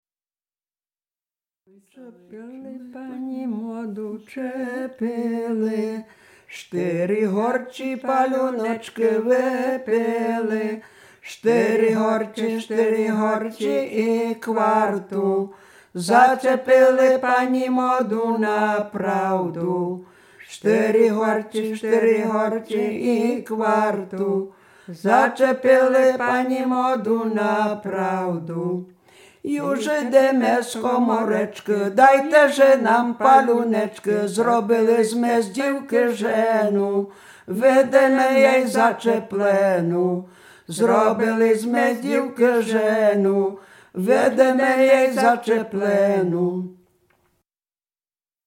Śpiewaczki z Sobina
Dolny Śląsk, gmina Polkowice, wieś Sobin
Weselna
Array weselne wesele oczepinowe przyśpiewki migracje